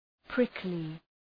Προφορά
{‘prıklı}